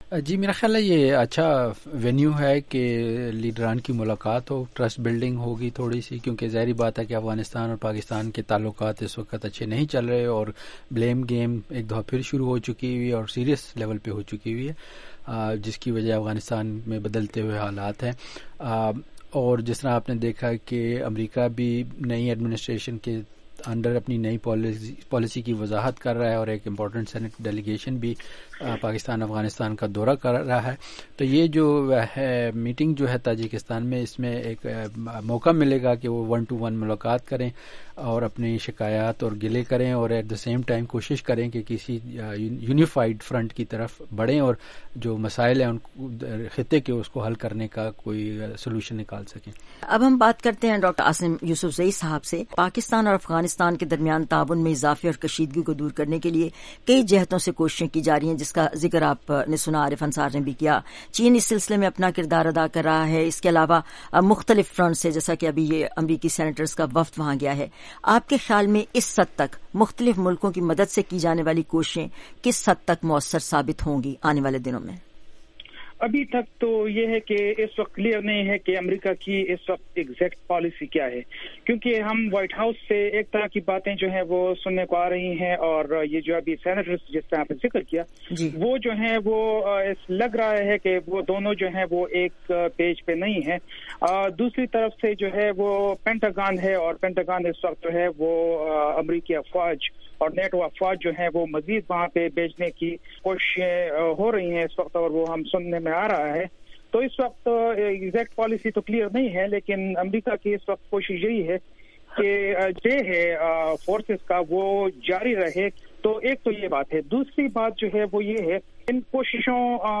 JR Discussion: Analysts' views on Pakistan-Afghanistan ties